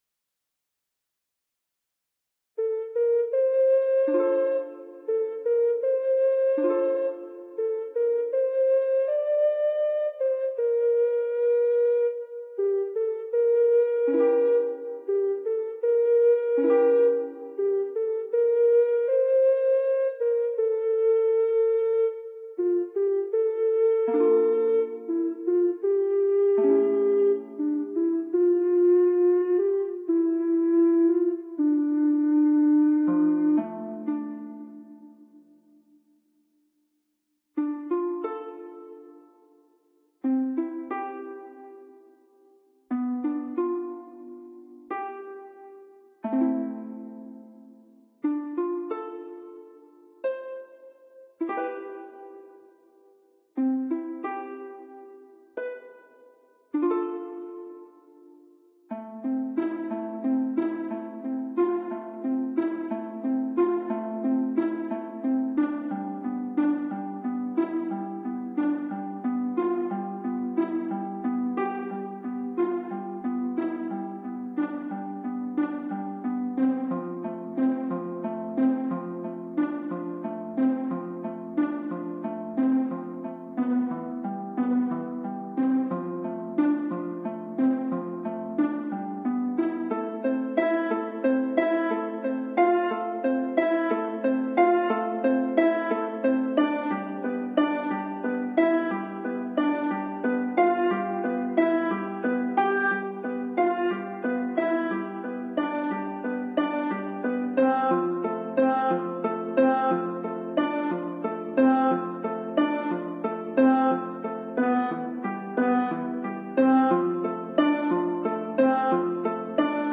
- Ambient
The link above are in low quality, if you want a better sound, you must download the FLAC ones from OGA.